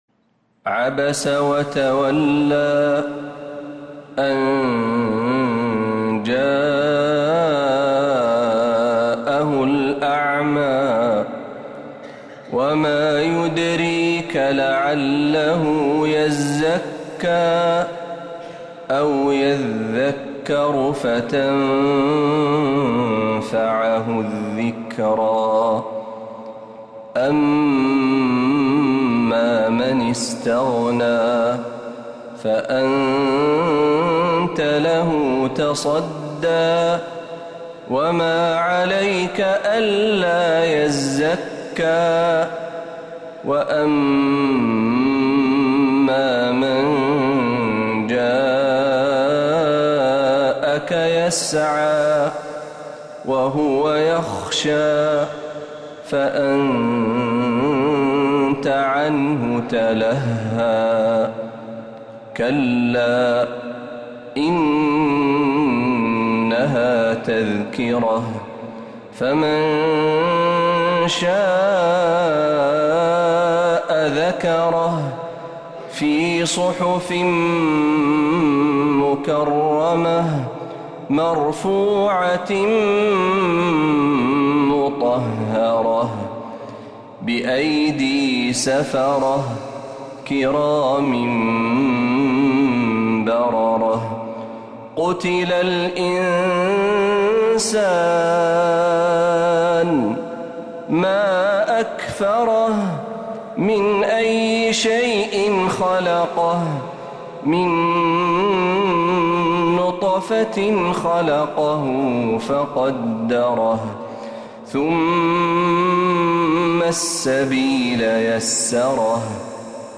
سورة عبس كاملة من عشائيات الحرم النبوي